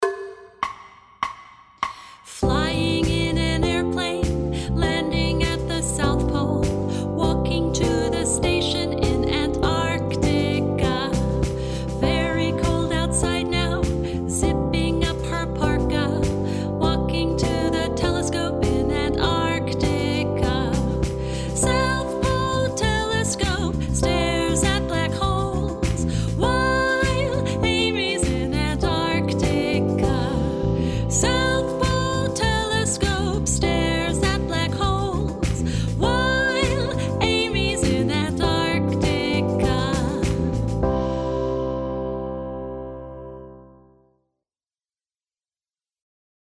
Amys-In-Antartica-Vocals.mp3